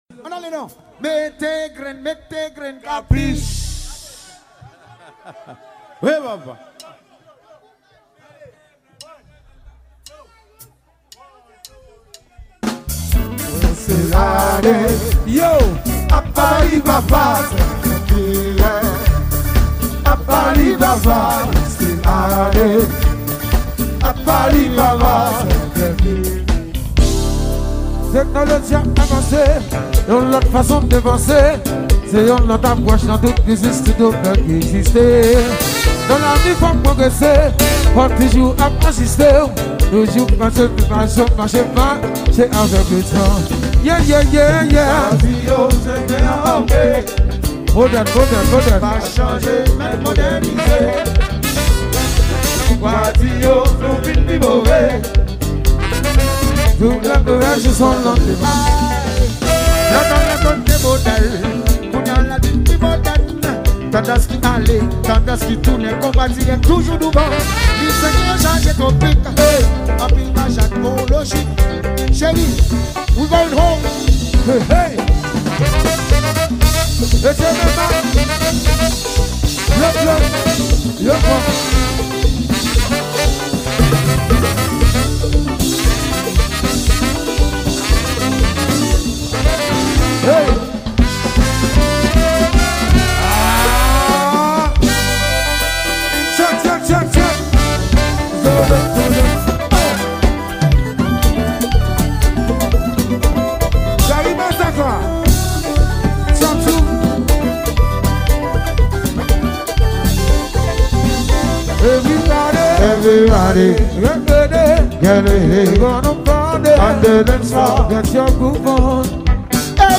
Genre: KONPA LIVE.